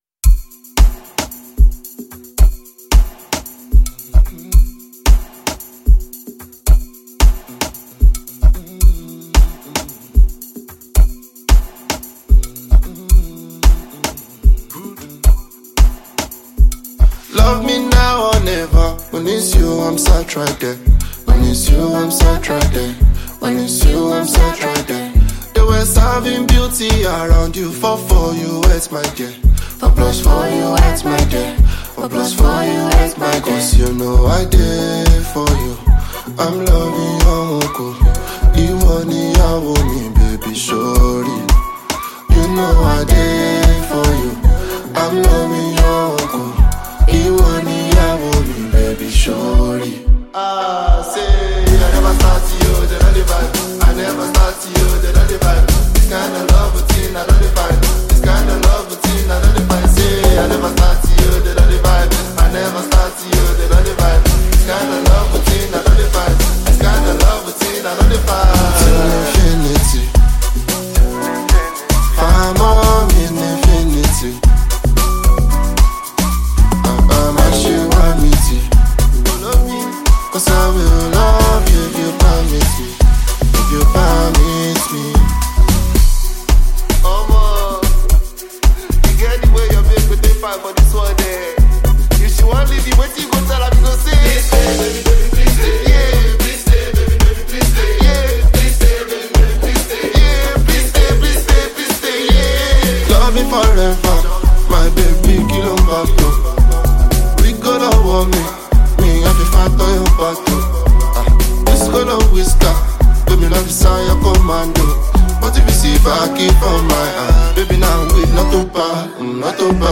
captivating audiences with his rhythmical sound and style